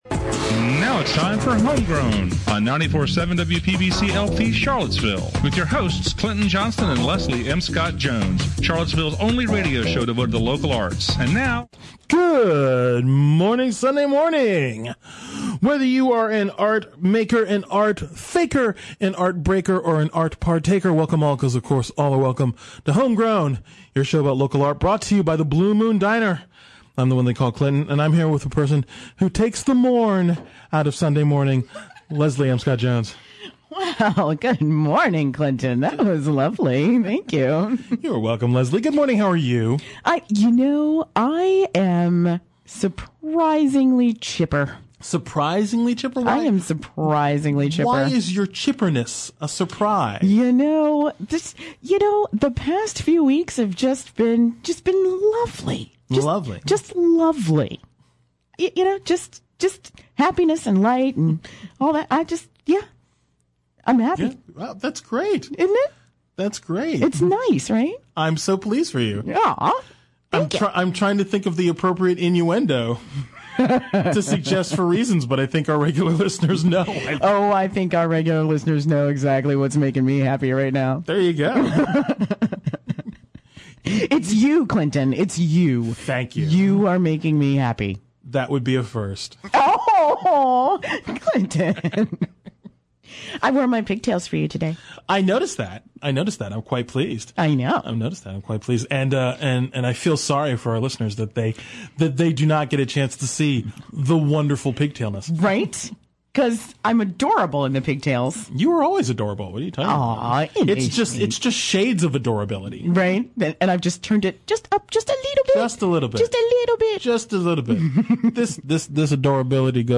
two guests who speak softly yet carry some big art.